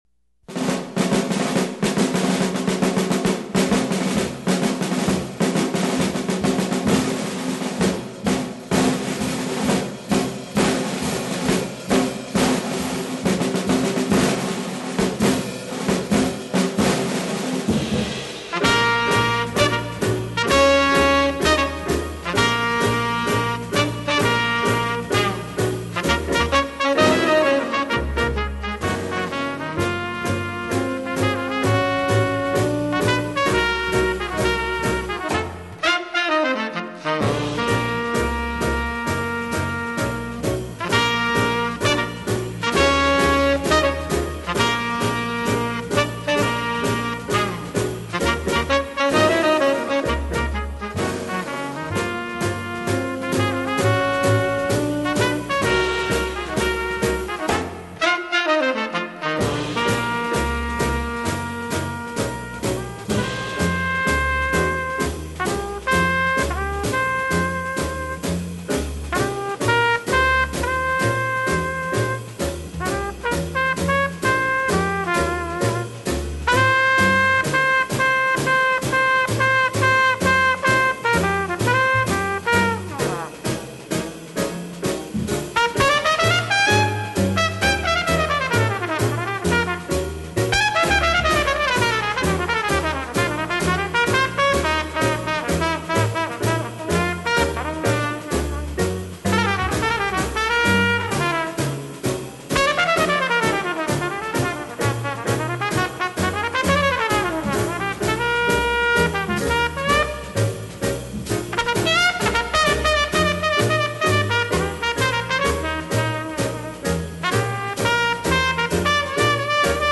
爵士音乐